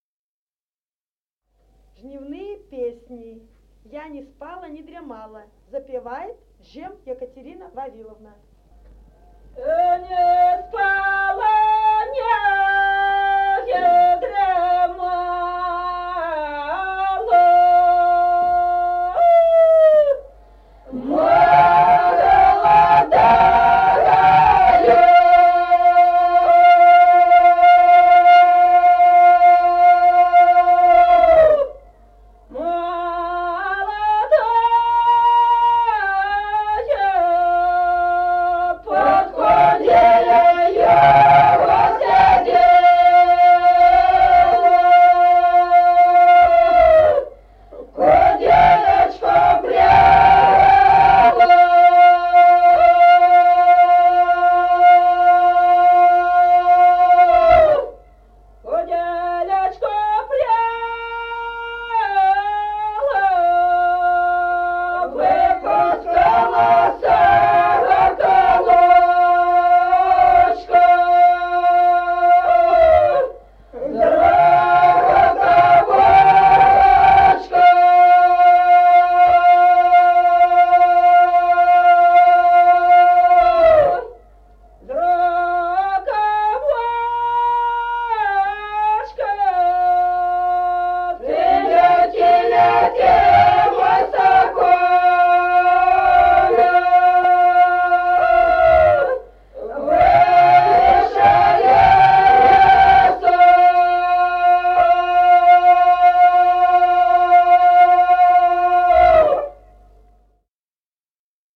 Народные песни Стародубского района «А не спала, не дремала», жнивная.